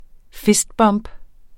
Udtale [ ˈfisd ˌbʌmb ]